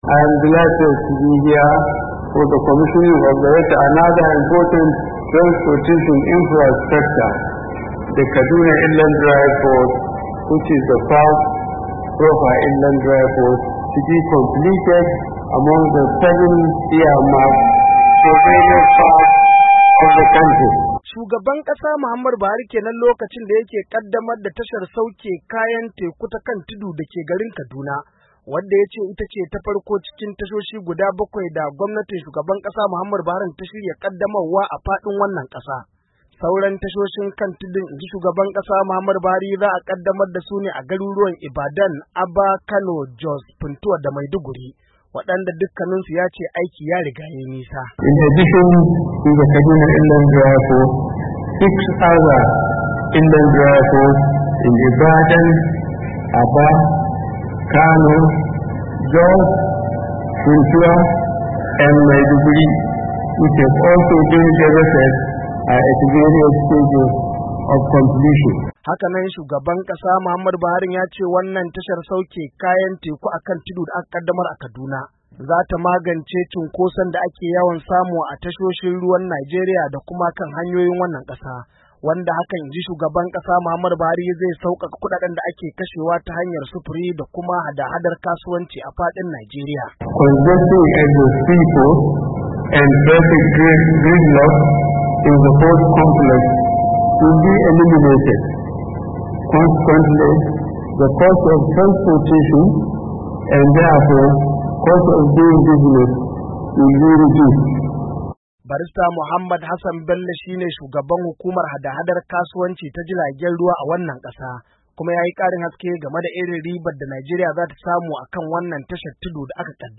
Shugaban Najeriya Muhammad Buhari a bikin kaddamar da tashar tekun kan tudu a Kaduna